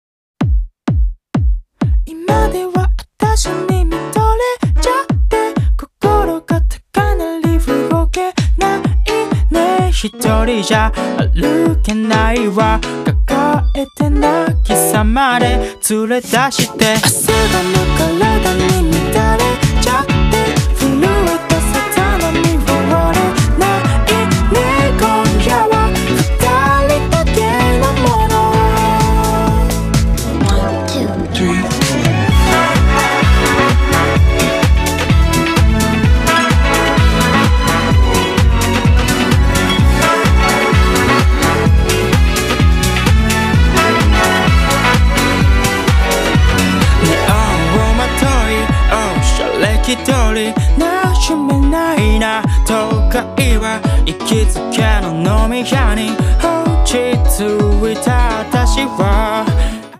声ほっそ